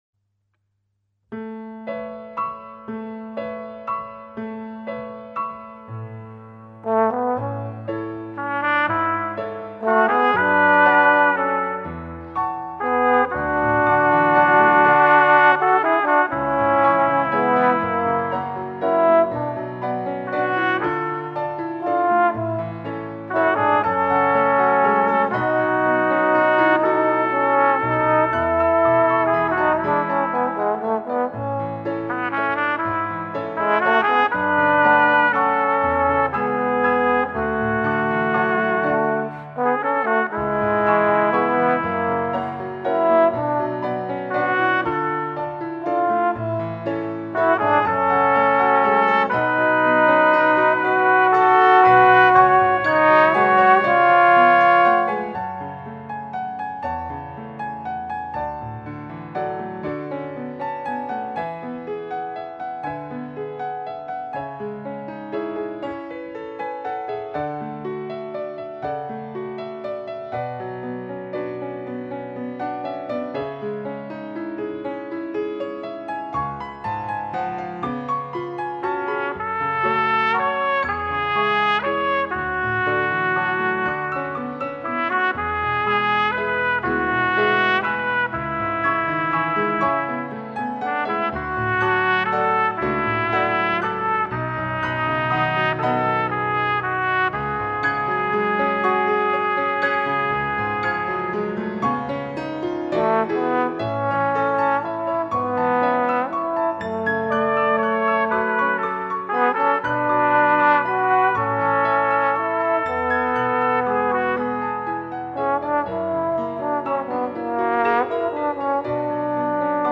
(1) Trp. Trb. Piano